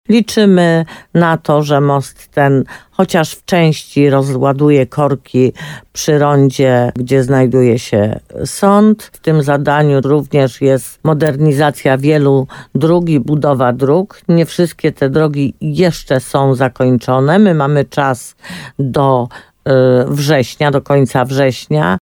Jak mówi burmistrz Jolanta Juszkiewicz, nowa przeprawa ma usprawnić ruch w mieście.